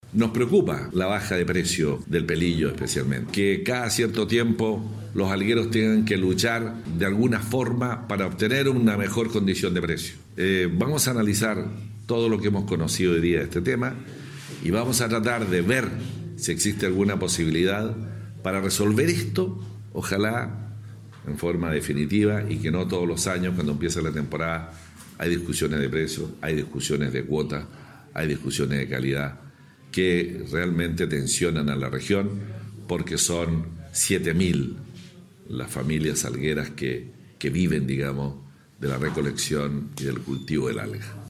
El intendente Harry Jurgensen expresó que para poder ayudar a superar este problema esperan poder buscar herramientas para sostener económicamente este rubro, ya que muchas familias dependen de la comercialización de las algas.
07-ALGA-INTENDENTE.mp3